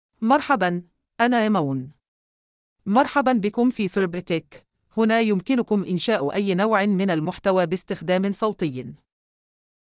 FemaleArabic (Libya)
ImanFemale Arabic AI voice
Voice sample
Female
Iman delivers clear pronunciation with authentic Libya Arabic intonation, making your content sound professionally produced.